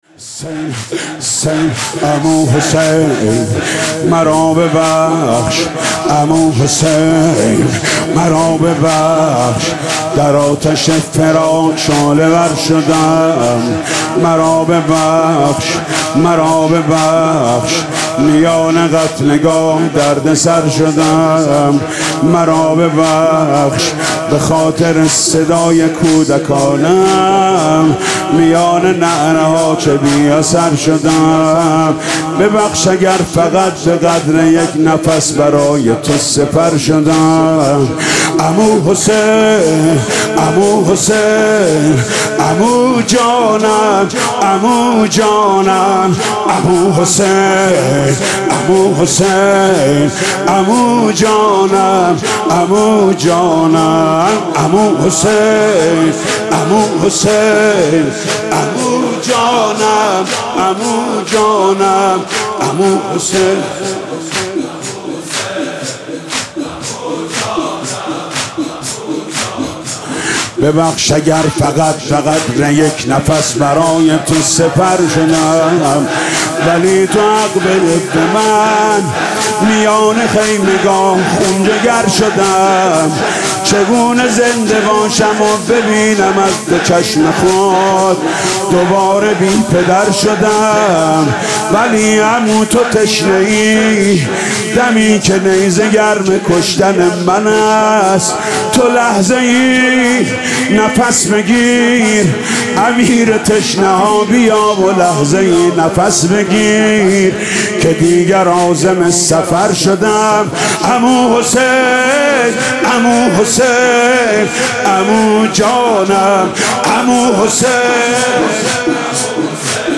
مداحی شب پنجم محرم + صوت